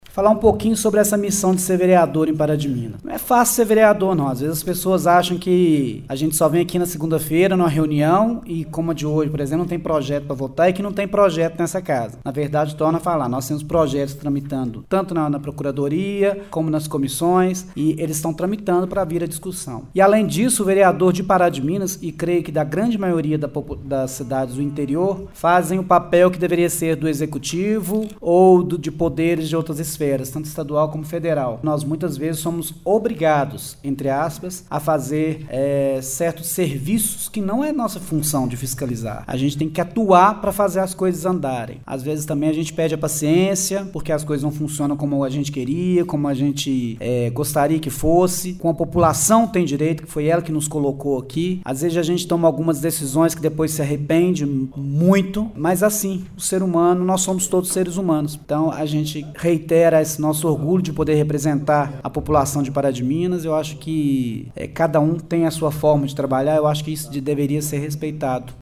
Como já é de praxe foi aberta a parte dos oradores inscritos para pronunciamento de assuntos diversos na tribuna livre do Poder Legislativo.
Durante seu pronunciamento o vereador Rodrigo Varela Franco (PSD) lembrou a todos os presentes da data comemorativa. Ele ressaltou que ao contrário do que muitos pensam, atuar como vereador não é uma tarefa fácil: